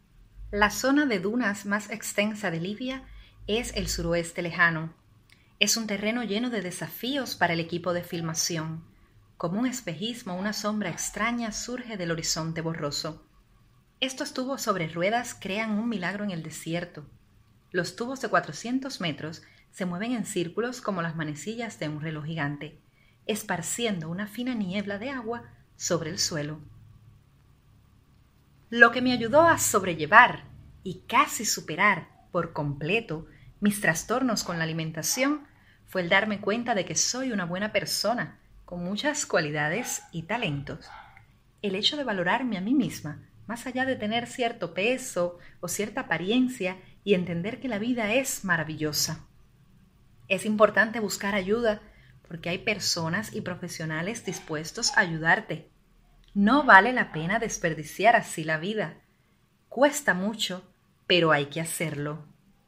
Locutora y narradora Voice over
Sprechprobe: Sonstiges (Muttersprache):
Prueba de locución formal.mp3